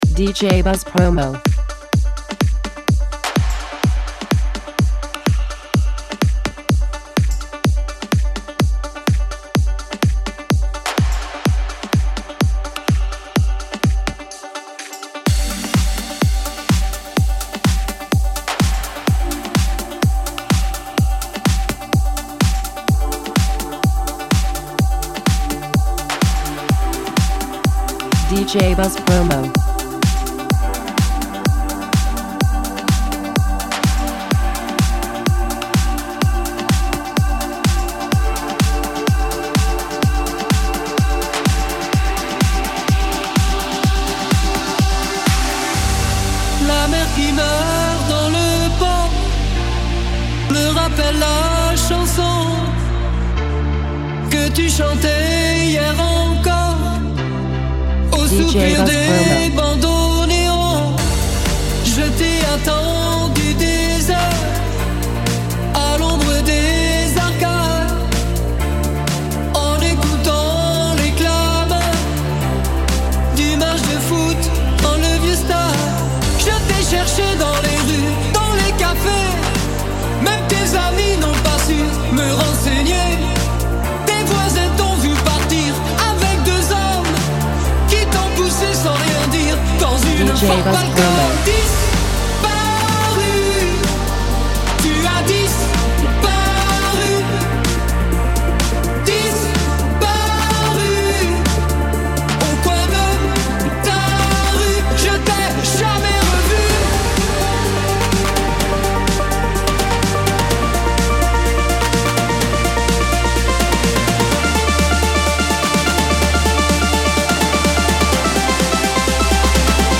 synthpop anthem
Extended
fresh, electrifying remix